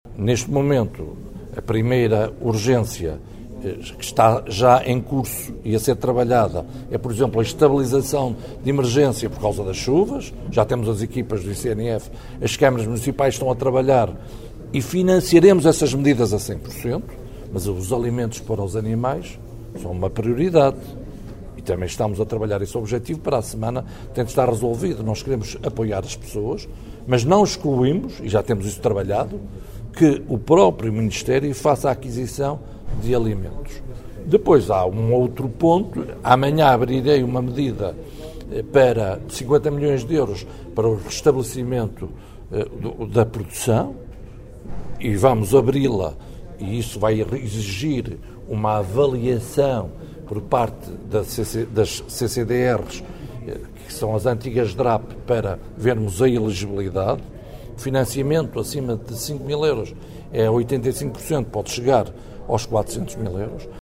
Declarações do ministro da Agricultura, José Manuel Fernandes, ontem à tarde, em Vila Pouca de Aguiar, onde na semana passada arderam mais de 10 mil hectares de floresta, mato e área agrícola.